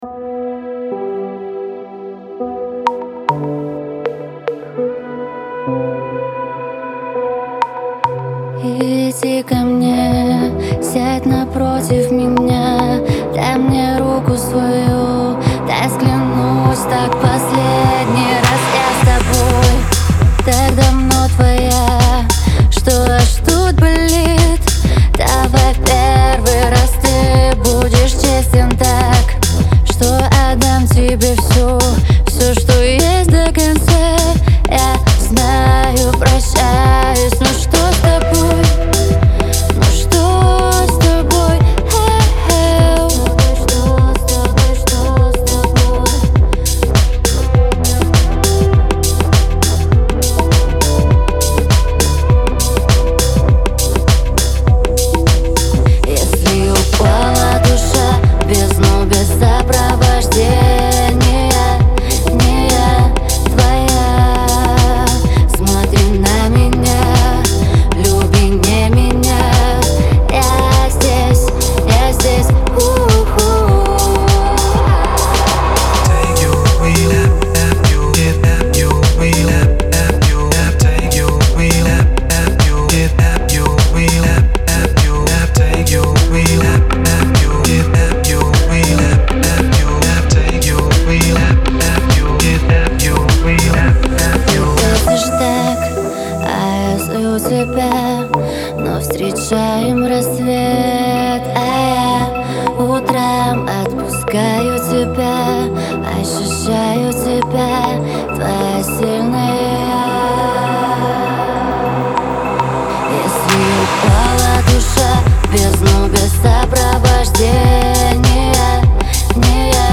мощный вокал и выразительные мелодии